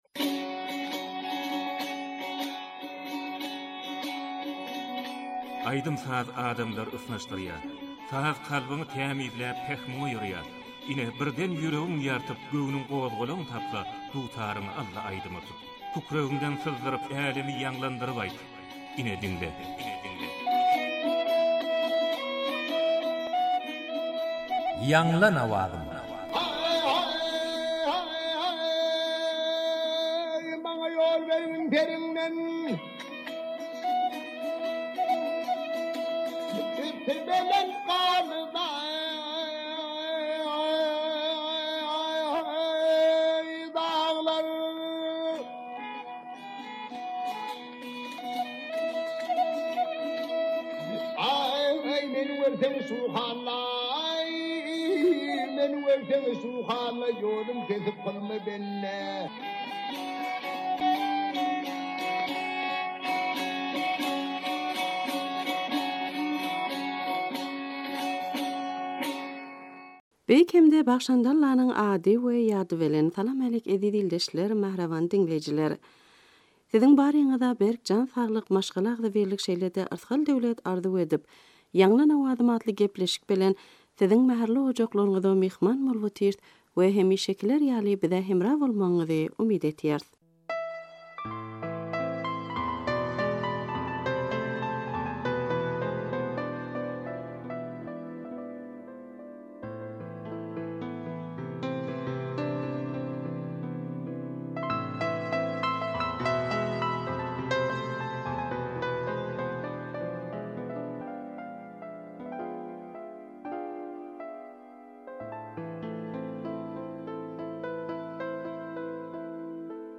turkmen owaz aýdym